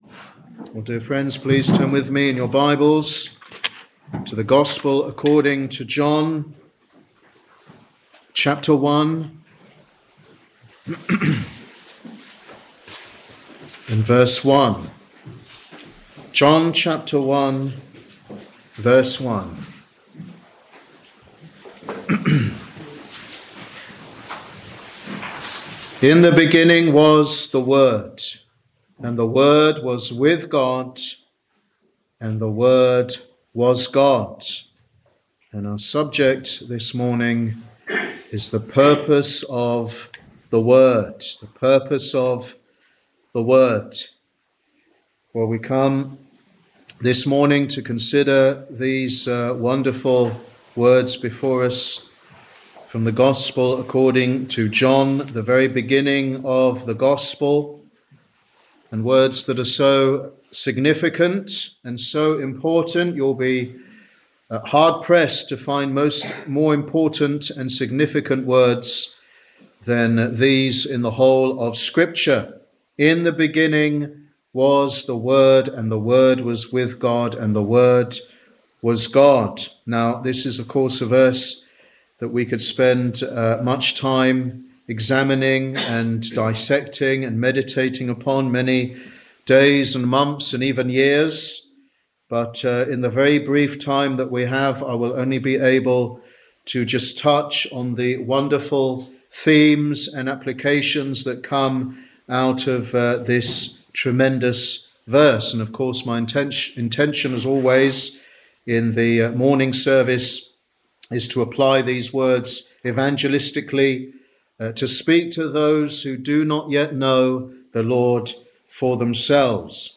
Sunday Evangelistic Service